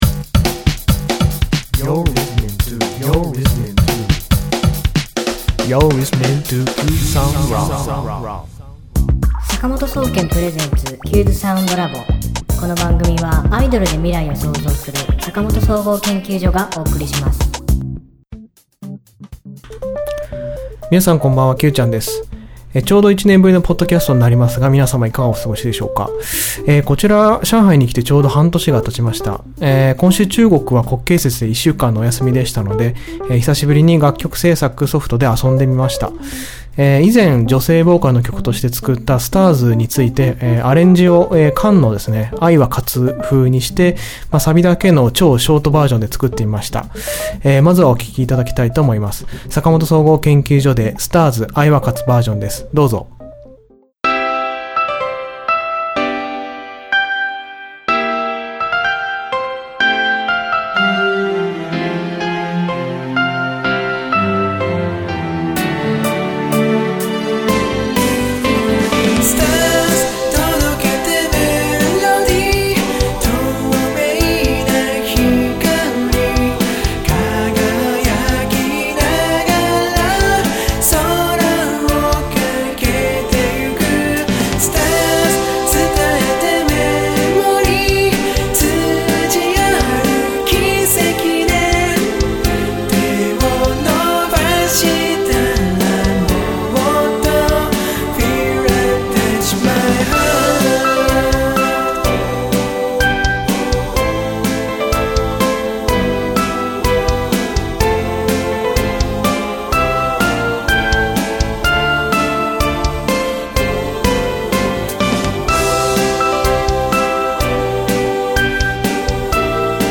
坂本総研ファンならご存知のあの曲をKANの『愛は勝つ』風にアレンジしてみました。
今週の挿入歌